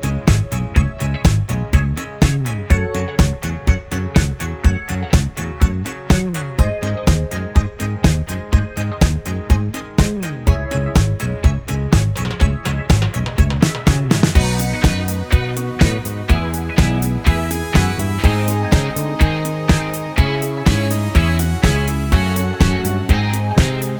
no Backing Vocals Disco 3:38 Buy £1.50